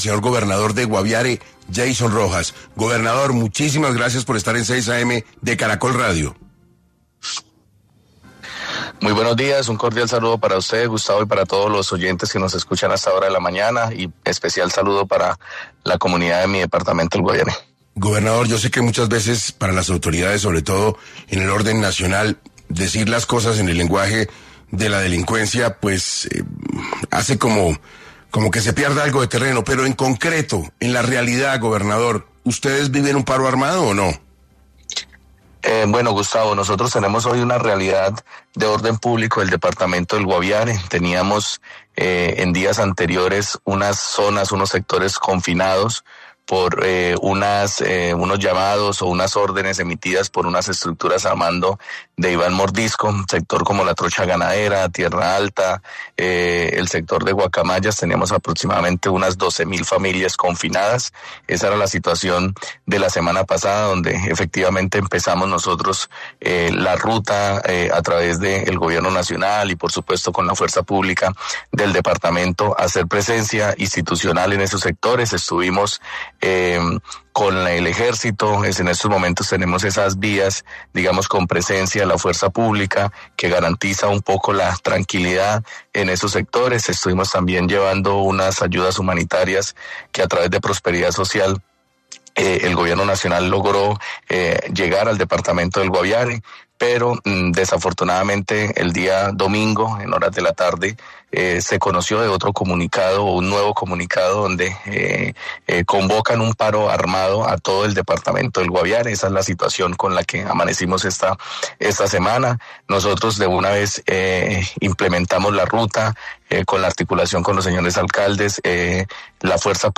El gobernador de Guaviare, Jason Rojas, informó en 6AM de Caracol Radio sobre la grave situación de orden público en el departamento, donde un paro armado decretado por estructuras al mando de alias ‘Iván Mordisco’ ha confinado a cerca de 30 mil familias en la zona rural.